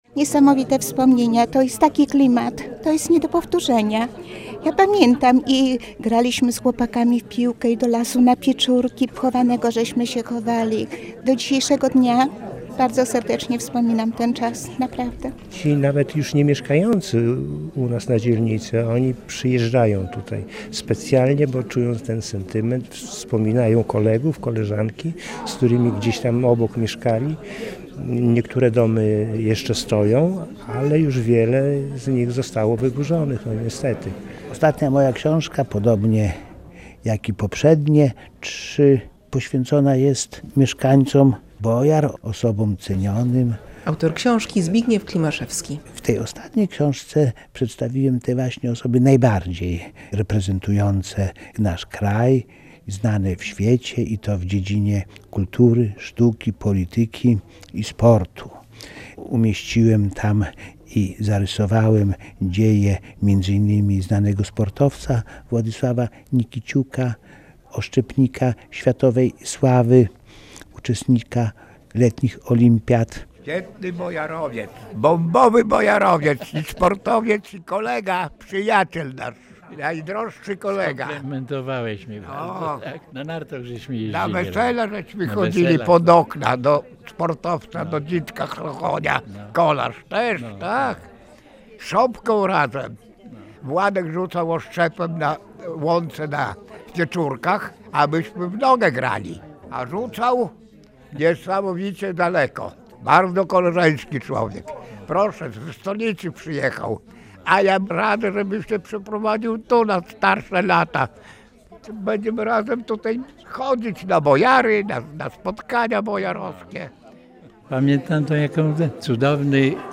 Promocja publikacji "Znani z Bojar" w Książnicy Podlaskiej zgromadziła pełną salę gości.
relacja